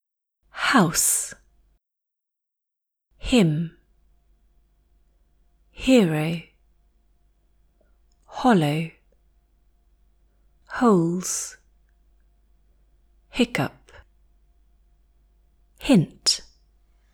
1. Missing out /h/ sounds, which are usually audible in English
Practice forming the /h/ sound like an RP speaker: with your tongue in a natural resting position, breathe out audibly before transitioning into the next sound.